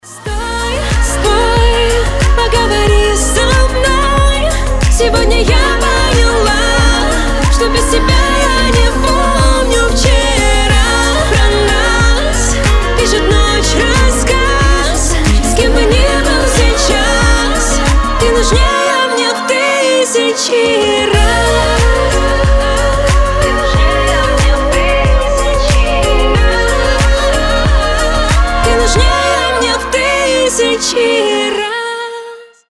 • Качество: 320, Stereo
поп
красивый женский голос